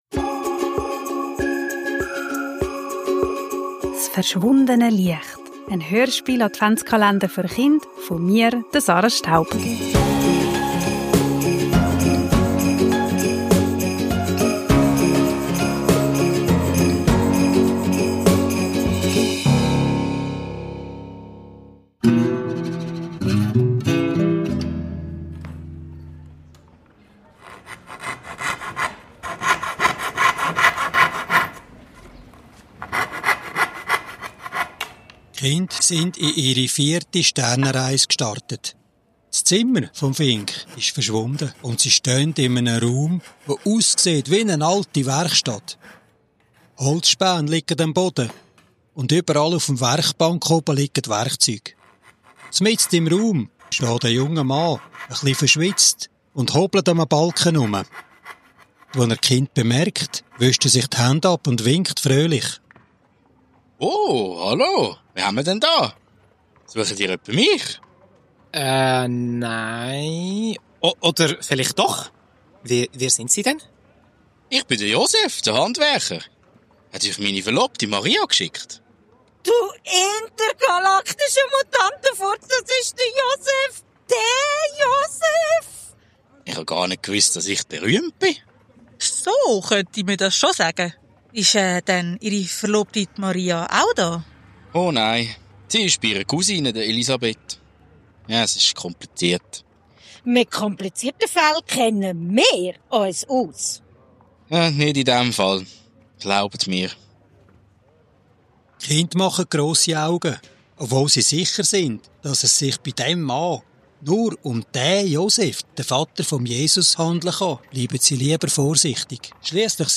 Kinder, Advent, Hörspiel, Weihnachten, Kindergeschichte